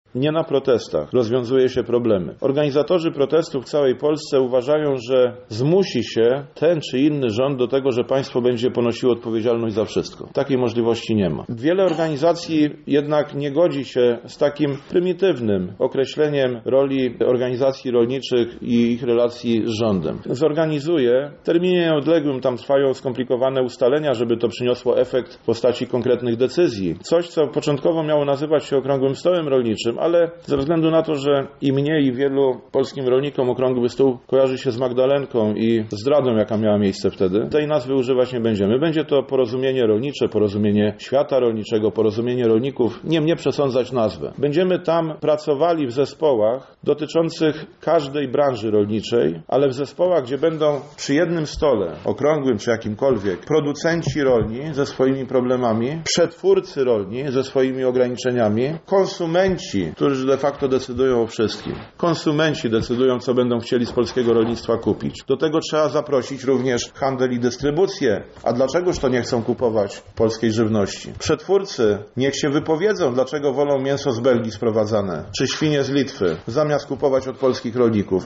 O samej kwestii protestów wypowiedział się również minister rolnictwa, Jan Krzysztof Ardanowski: